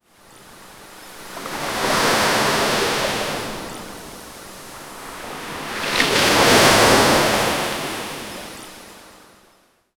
Royalty-free ocean sound effects
ocean-waves-crashing-on-s-obotplel.wav